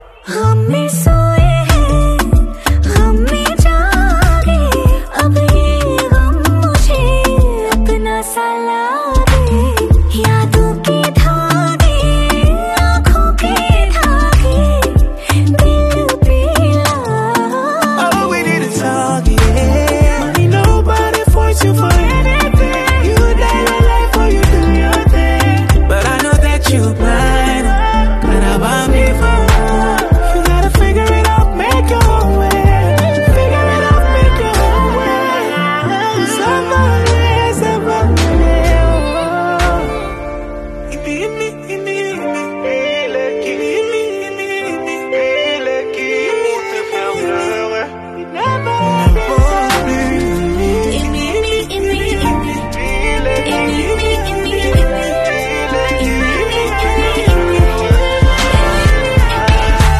peppy music